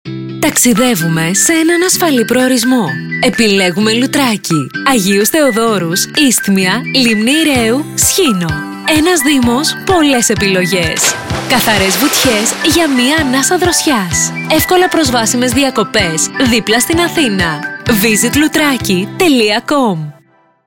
Με χαρά ακούσαμε σήμερα έναν κορυφαίο ραδιοφωνικό σταθμό των Αθηνών, τον Ρυθμό 94,9 του ΑΝΤ1 να “παίζει” σποτ διαφημιστικής προβολής για το Λουτράκι!